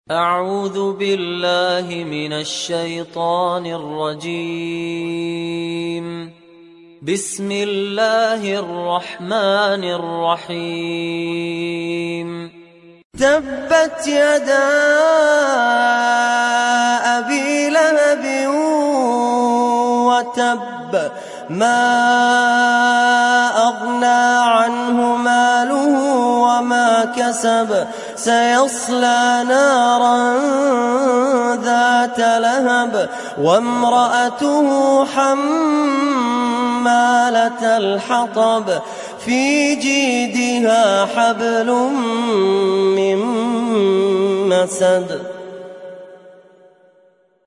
تحميل سورة المسد mp3 بصوت فهد الكندري برواية حفص عن عاصم, تحميل استماع القرآن الكريم على الجوال mp3 كاملا بروابط مباشرة وسريعة